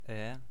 Ääntäminen
US : IPA : /wɪð/